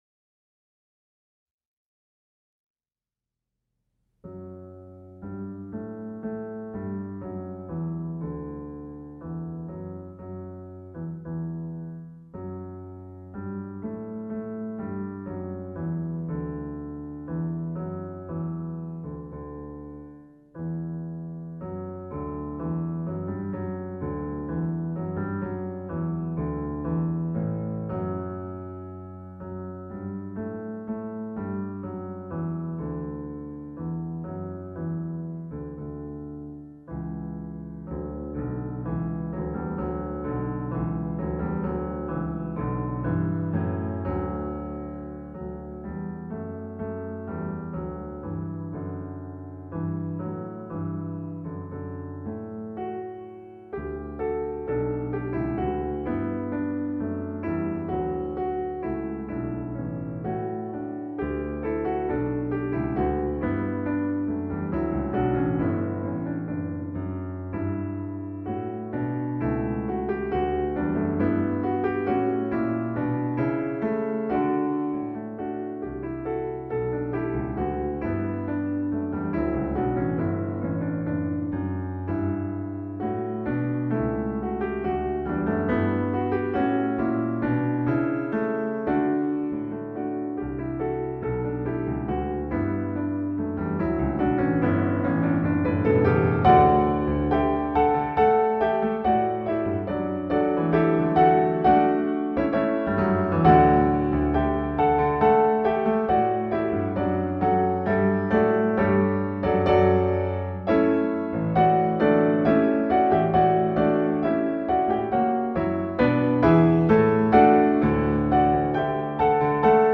Posłuchaj fragmentu symfonii w aranżacji fortepianowej: